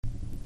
初期ラガ・ヒップホップの現場の空気を閉じ込めたようなサウンドです。
DANCE HALL